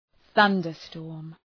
Προφορά
{‘ɵʌndər,stɔ:rm}